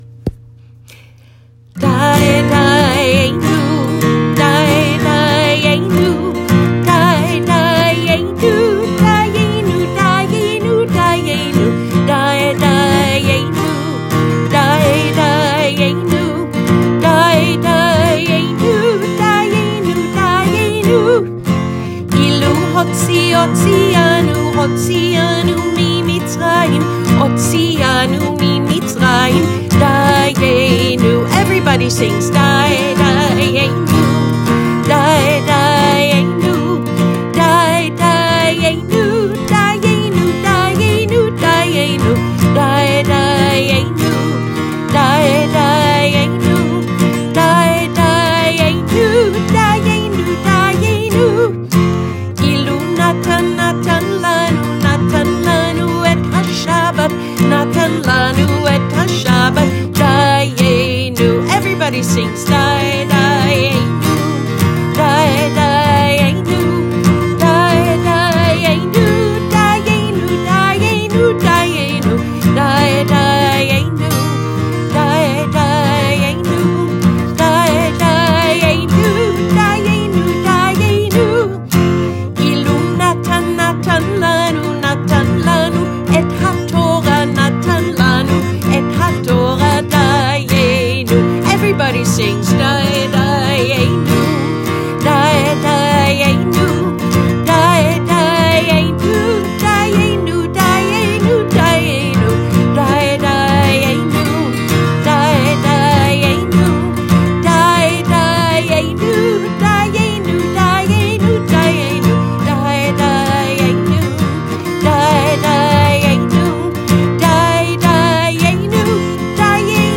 4-dayenu-all-pg-34-a-family-haggadah.m4a